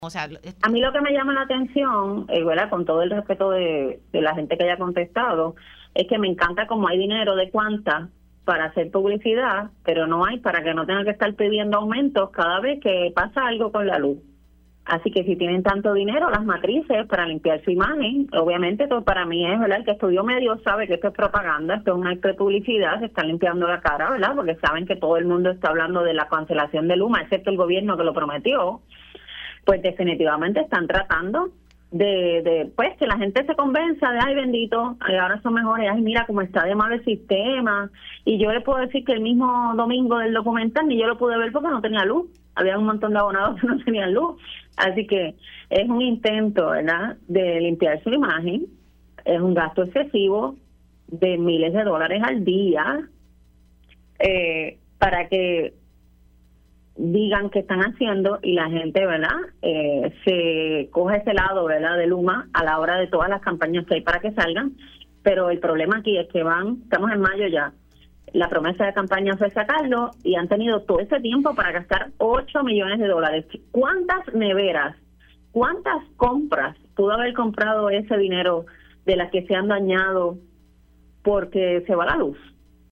215-ADA-ALVAREZ-SENADORA-PPD-SOSTIENE-GASTOS-MILLONARIOS-PARA-LIMPIAR-LA-CARA-DE-LUMA-ENERGY.mp3